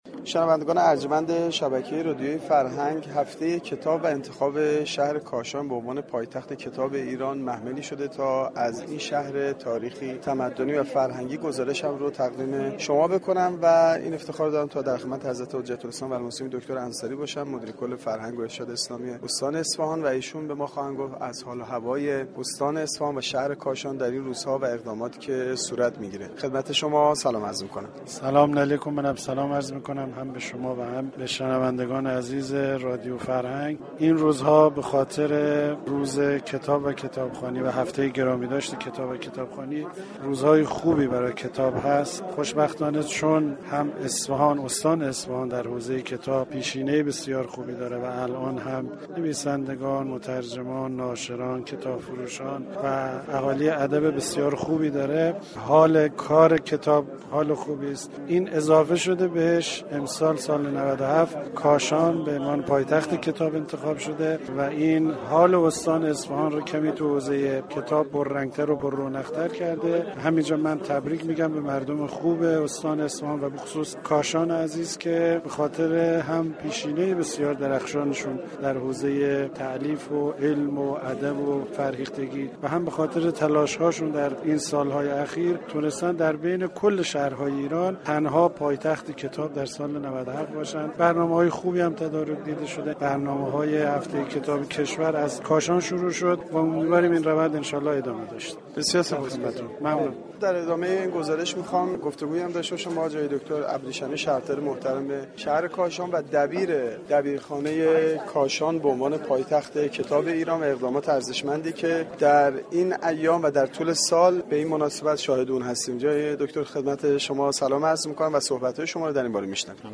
شهر كاشان در سال 97 عنوان پایتخت كتاب ایران را از آن خود كرد به همین مناسبت و با آغاز هفته ی كتاب حجت الاسلام دكتر انصاری مدیر كل فرهنگ و ارشاد اسلامی استان اصفهان در گفتگوی اختصاصی با گزارشگر رادیو فرهنگ درباره ی اقدامات و برنامه های هفته ی كتاب در استان اصفهان و شهرستان كاشان گفت : این روزها به مناسبت هفته ی كتاب و كتابخوانی روزهای خوب و مباركی در حوزه ی كتاب برای استان اصفهان و شهر كاشان فراهم شده است .